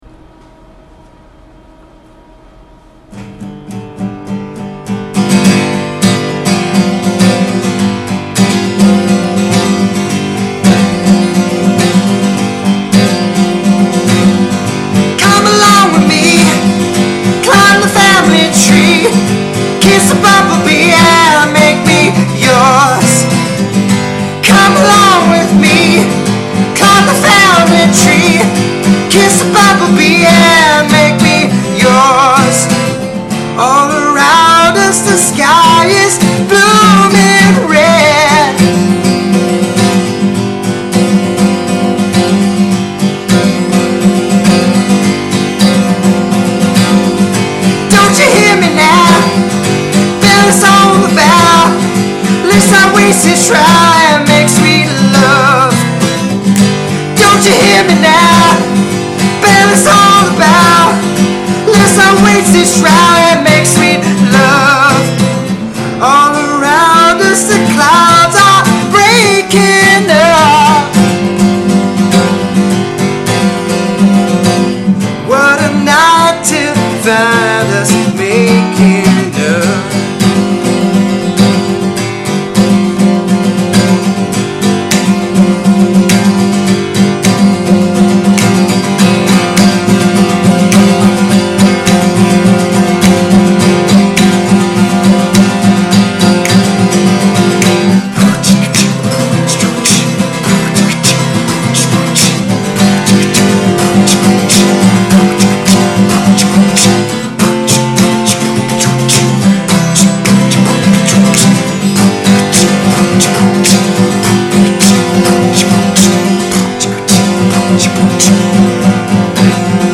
I just made a rough version of a new song I wrote, called Green. One version is solo, the other has the vocals doubled.
Keep in mind also that I will be re-recording this (with stuff to fill in the instrumental break), so the levels will be mo’ better balanced and the doubled vocals mo’ better matching.